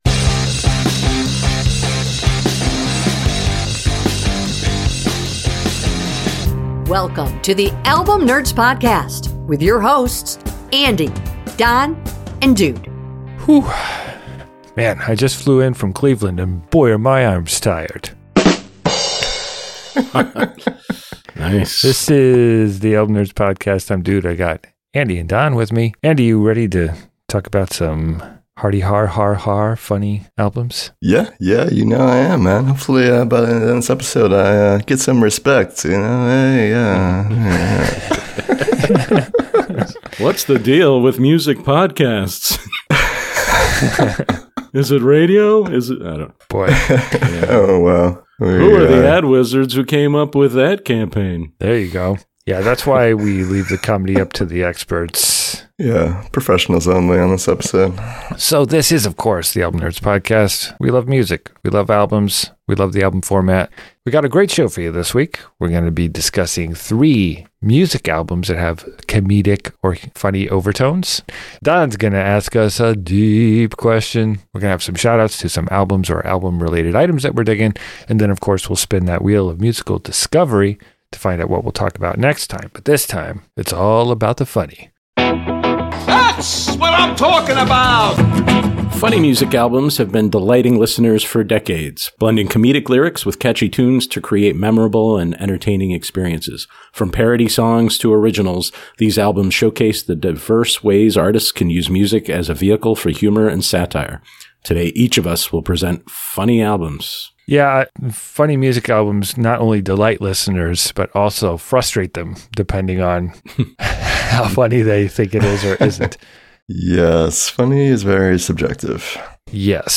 Album picks on a range of topics selected by the all knowing Wheel of Musical Destiny. Three friends and music nerds discuss classic albums across a variety of genres including rock, metal, country, hip-hop, rnb and pop.